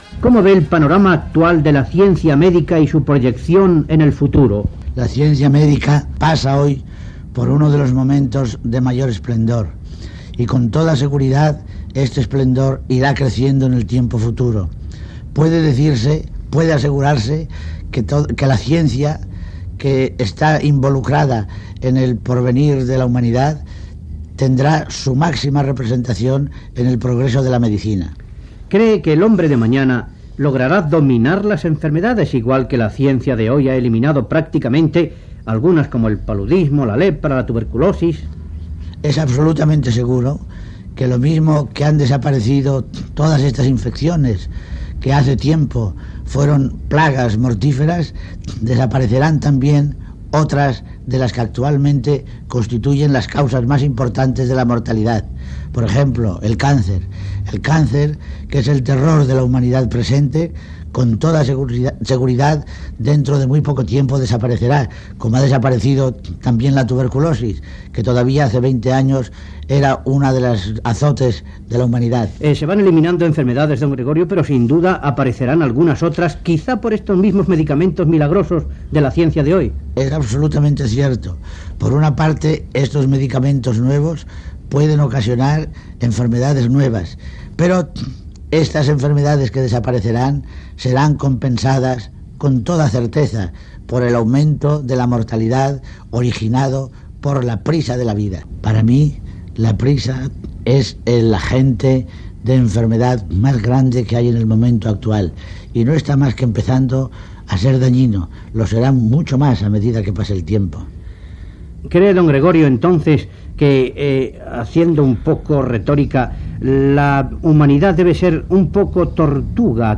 Entrevista al doctor Gregorio Marañón